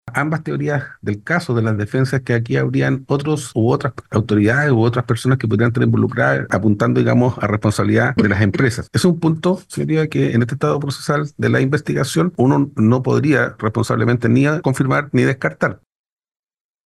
Por su parte, el fiscal metropolitano Occidente, Marcos Pastén, declaró que aún es prematuro confirmar cualquier teoría.